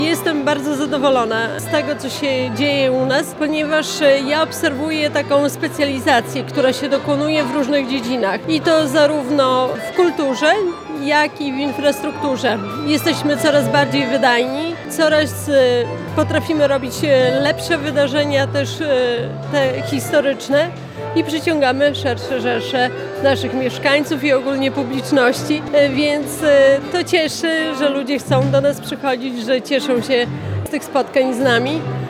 Podczas uroczystej gali zorganizowanej w Filharmonii Kameralnej im. Witolda Lutosławskiego, starostwo łomżyńskie świętowało potrójny jubileusz.
Czas pełen wyzwań, wielu inwestycji i zrealizowanych planów – tak podsumowuje minione lata Wicestarosta Powiatu Łomżyńskiego, Maria Dziekońska.